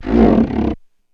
Index of /90_sSampleCDs/E-MU Producer Series Vol. 3 – Hollywood Sound Effects/Miscellaneous/Rubber Squeegees
SQUEEGEE 6.wav